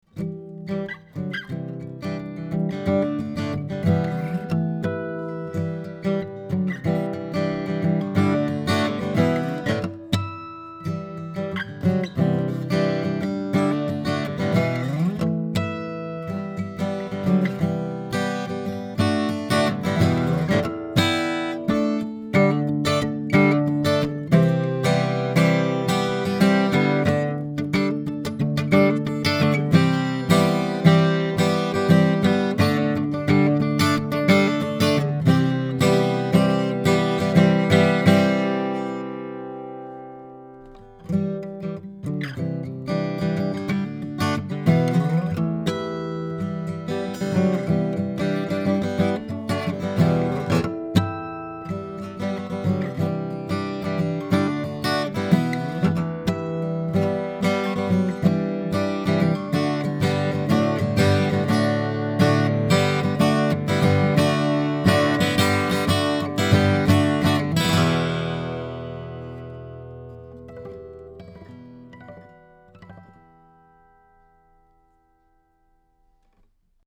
Here are some of my sound files of the BB4 equipped with an Audient Black Preamp -- just to show you the sound of the Black Series preamp, using a Blackspade Acoustics UM17 tube mic, and recorded into a Sony PCM D1 flash recorder, with no EQ or Compression: